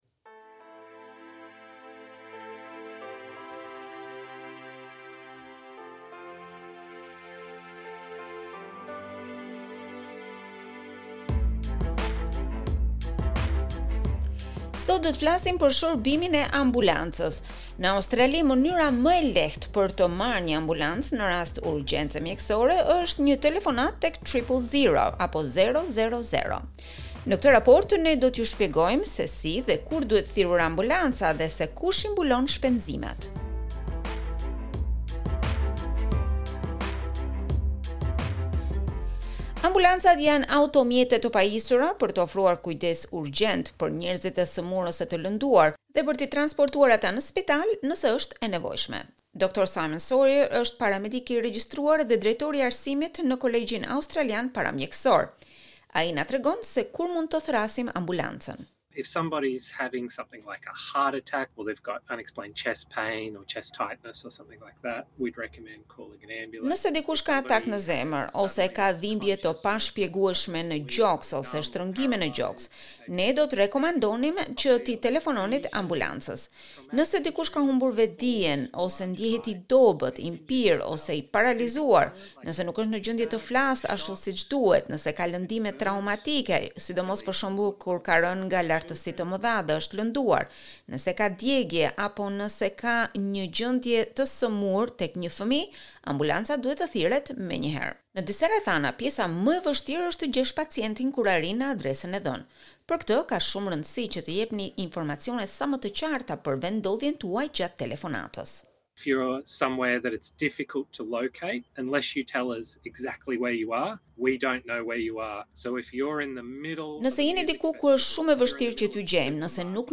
Në këtë raport ju shpjegojmë se si dhe kur duhet thirrur një ambulancë si dhe se kush i mbulon shpenzimet.